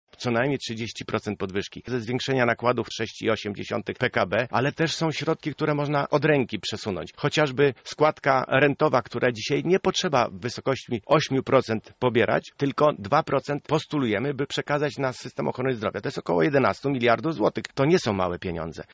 Taka deklaracja padła w rozmowie z Janem Łopatą w Porannej Rozmowie Radia Centrum.